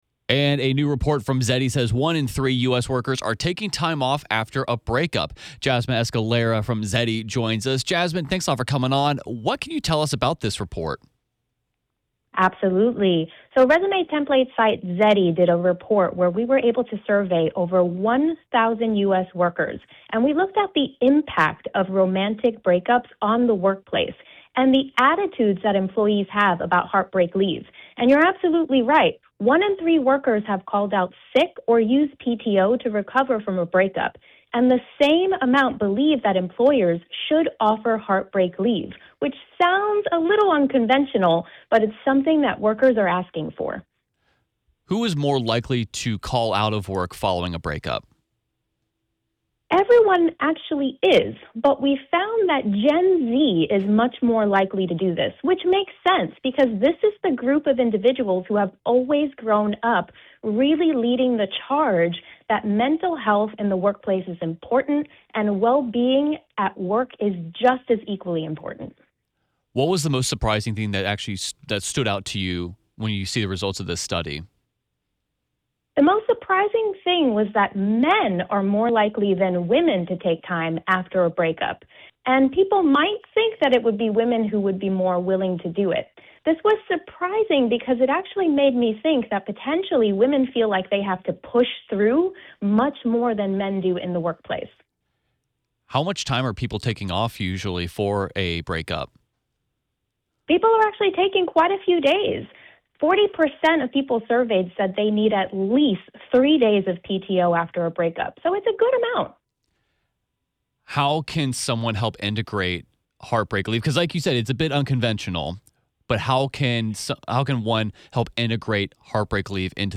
full interview.